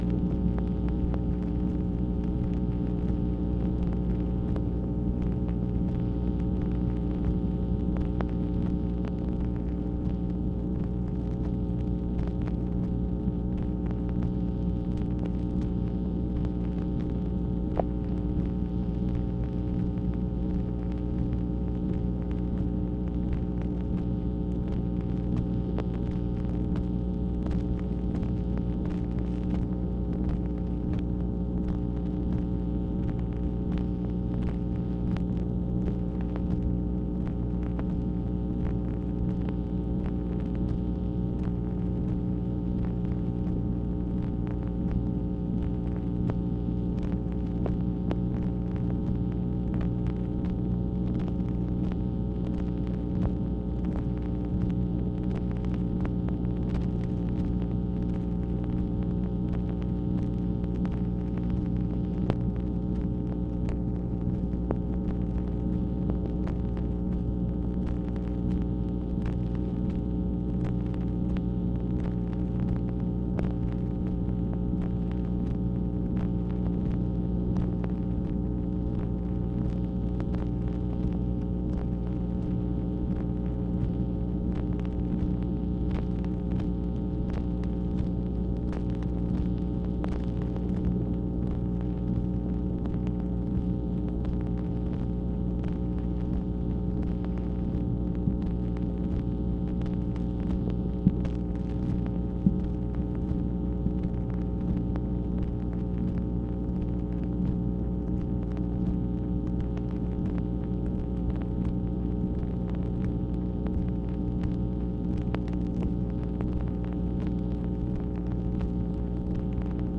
MACHINE NOISE, September 17, 1966